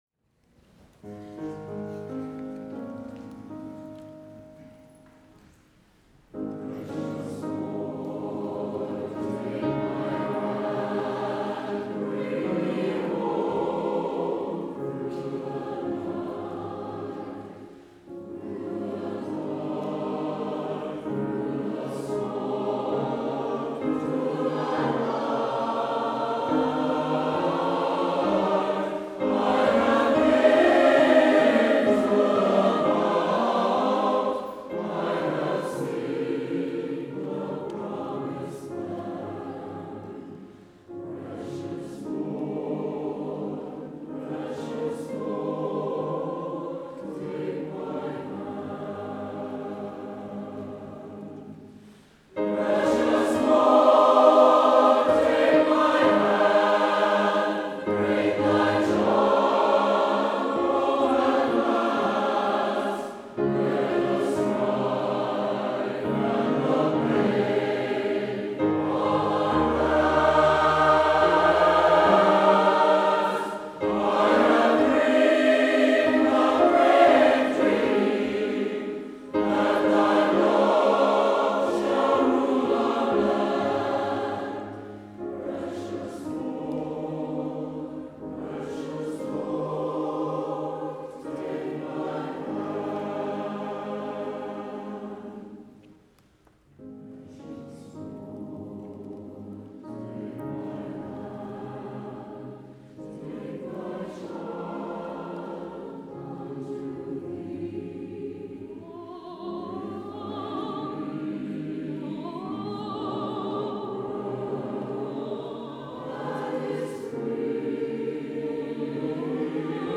Santa Barbara City College Choir Concert, Spring 2008
Concert Choir